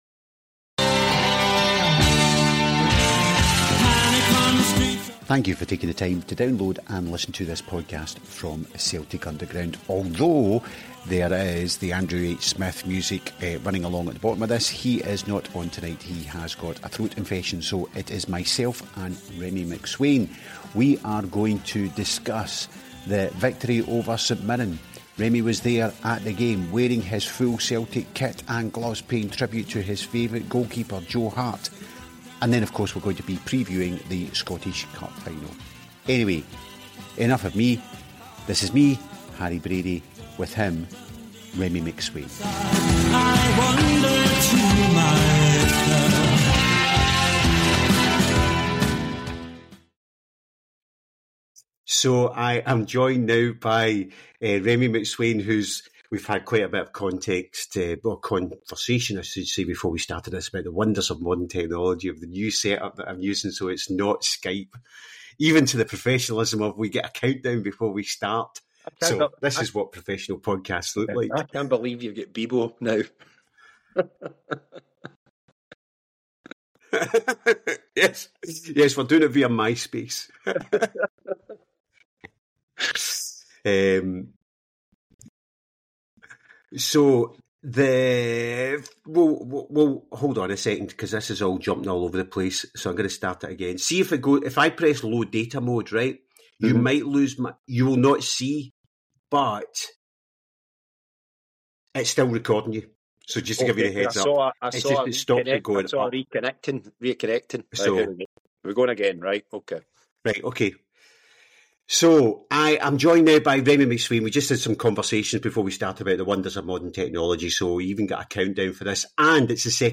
The audio is good because the crap bits are editted out.